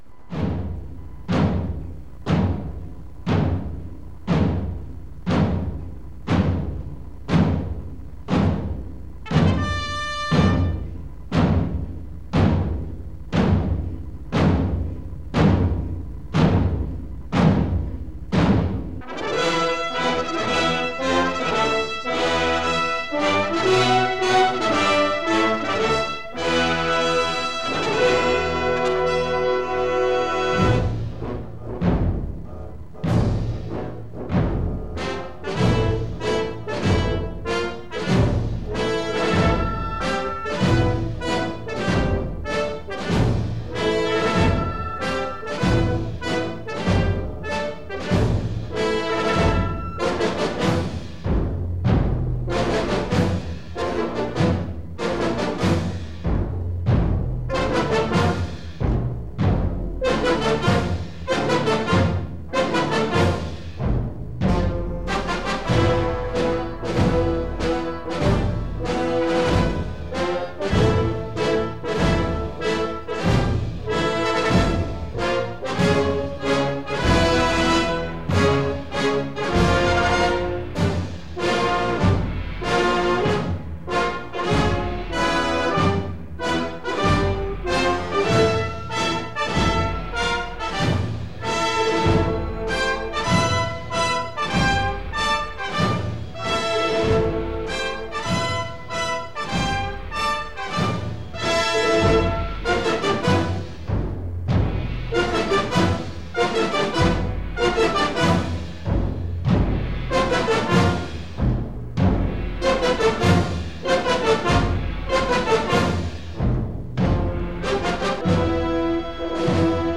Original track music: